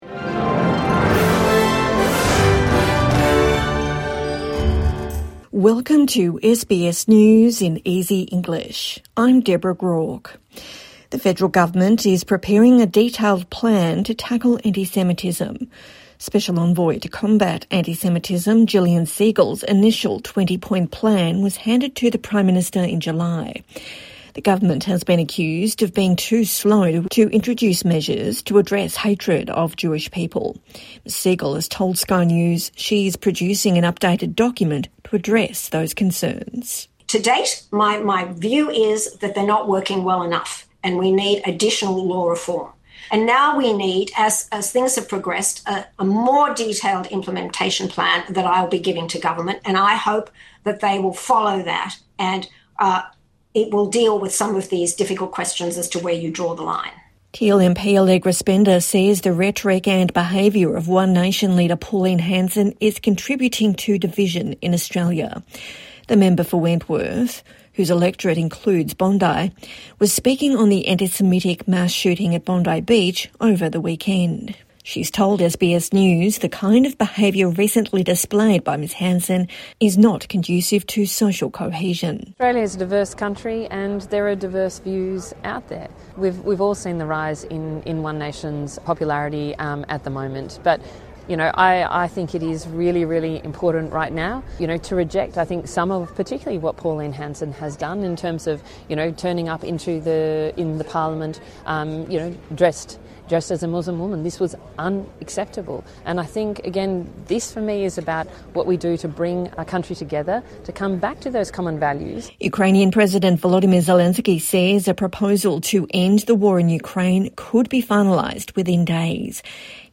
A daily 5-minute news wrap for English learners and people with disability.